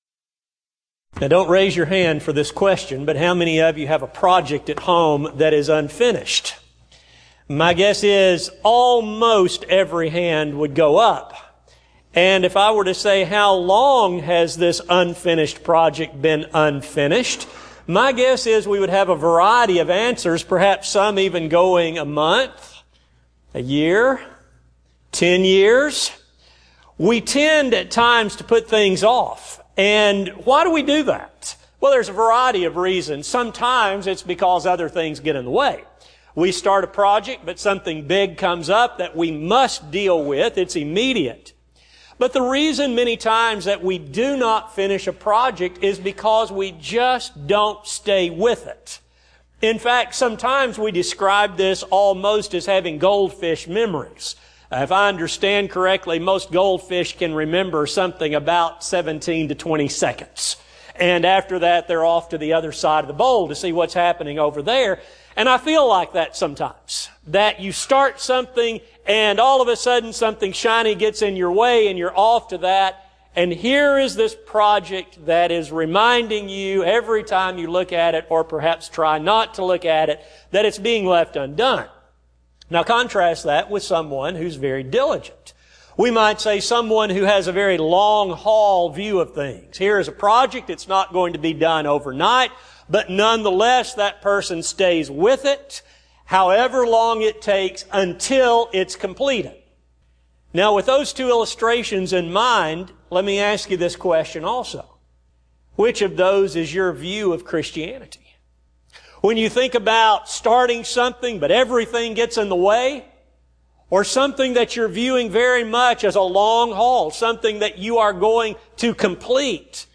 Partakers of the Divine Nature Service: Sun AM Type: Sermon